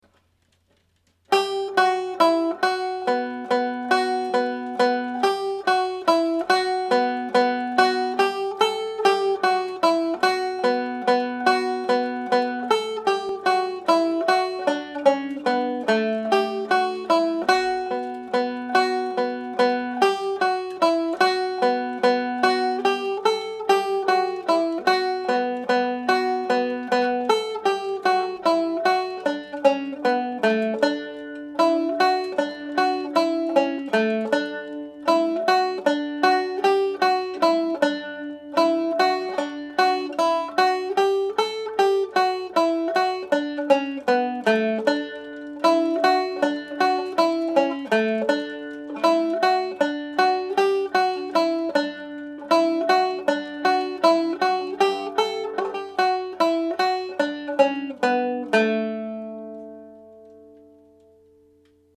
Pure Banjo
This is the second tune of the set of two slip jigs paired with Cathal McConnell’s. The highlighted F# can be played or left out.
The Humours of Whiskey played slowly